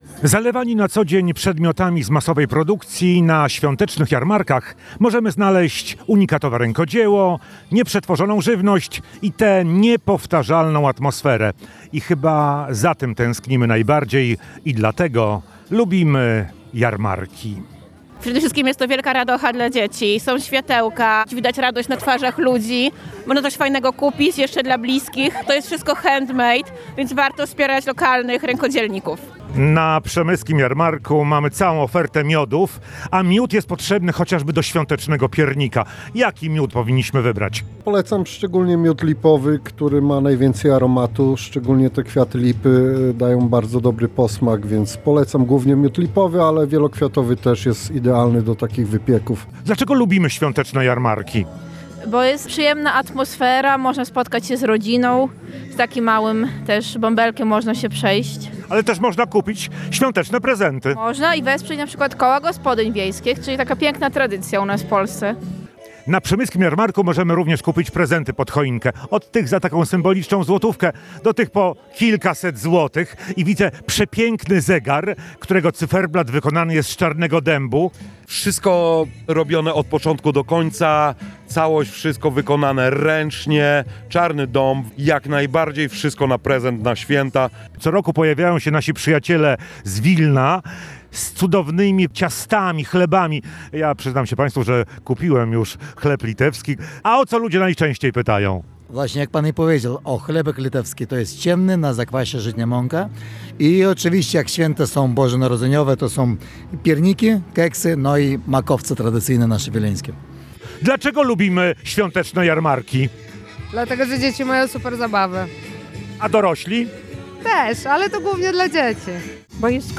[ZDJĘCIA] Przemyski Jarmark Bożonarodzeniowy • Relacje reporterskie • Polskie Radio Rzeszów
Przemyski-Jarmark-2025.mp3